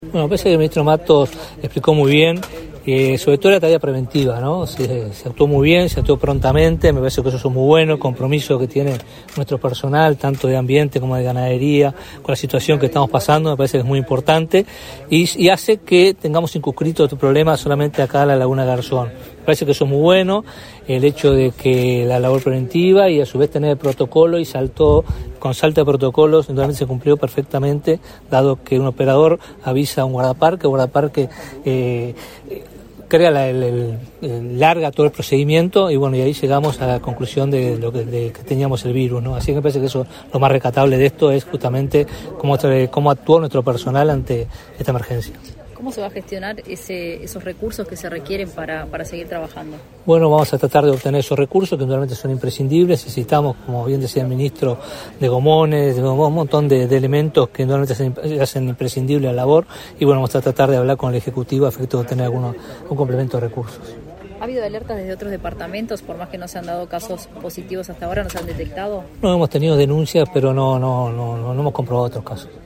Declaraciones a la prensa del ministro de Ambiente, Robert Bouvier